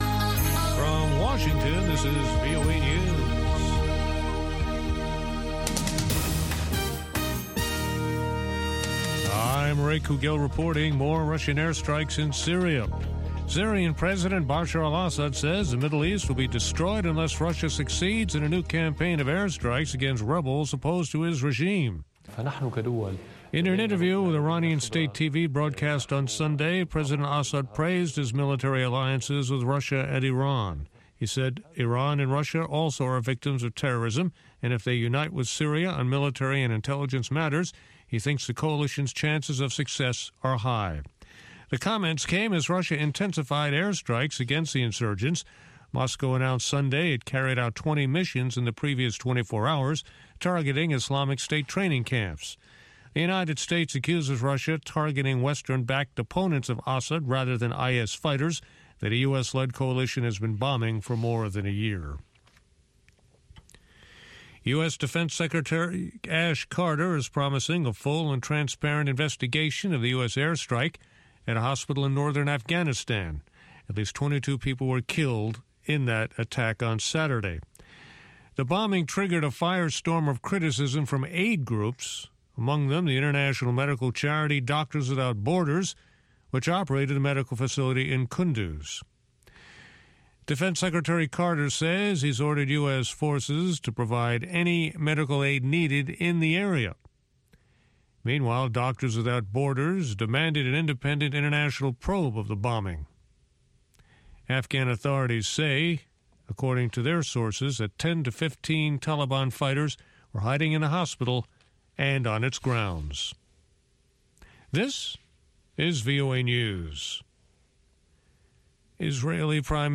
N’dombolo
Benga
African Hip Hop
the best mix of pan-African music